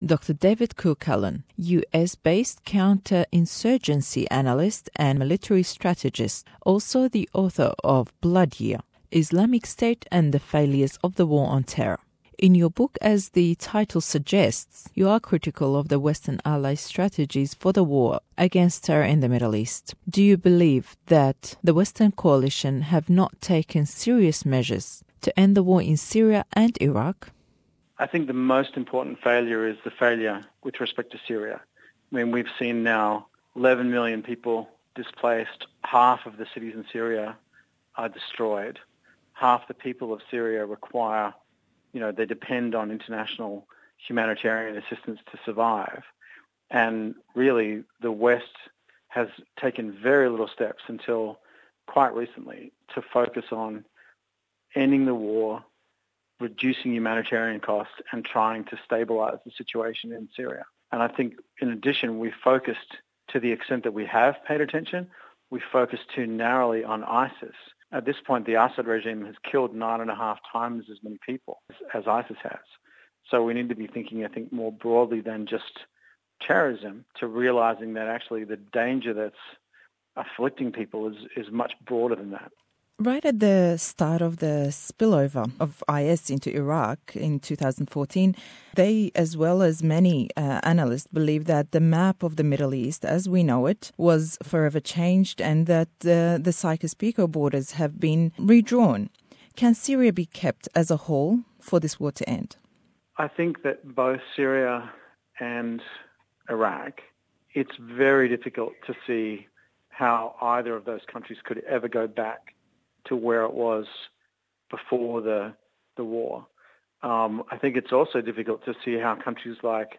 Parts I and II of our interview with Dr David Kilcullen who is a counter-insurgency analyst and military strategist based in the US. He was a senior advisor to General David Petraeus in 2007 and 2008, when he helped to design and monitor the Iraq War troop surge.